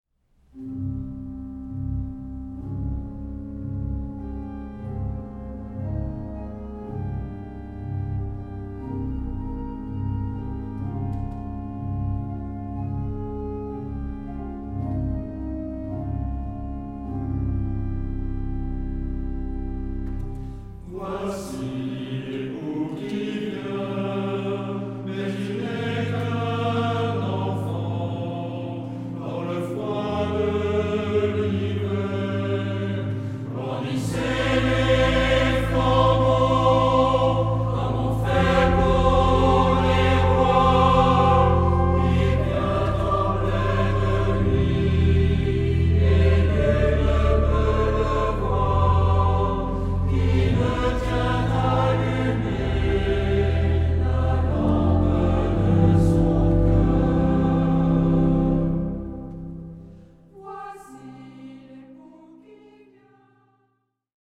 Genre-Style-Form: Sacred ; Hymn (sacred)
Mood of the piece: calm
Type of Choir: SAH  (3 mixed voices )
Instruments: Organ (1)
Tonality: C minor